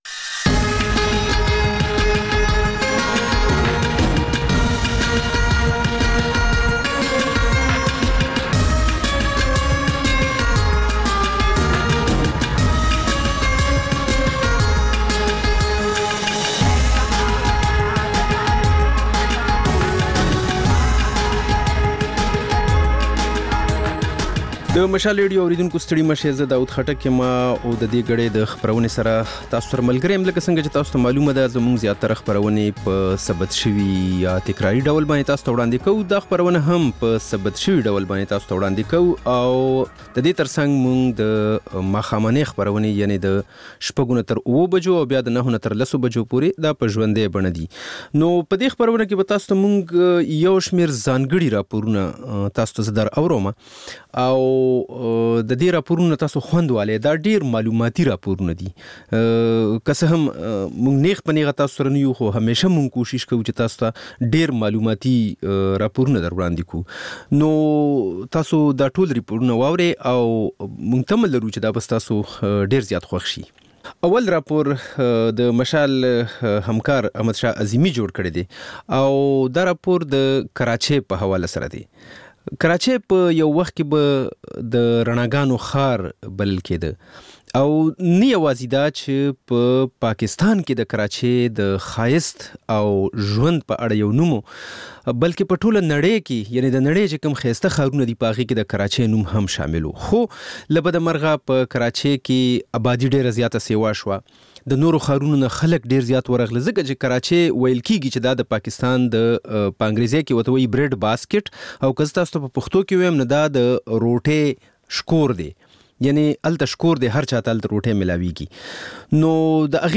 په دې خپرونه کې تر خبرونو وروسته بېلا بېل رپورټونه، شننې او تبصرې اورېدای شئ.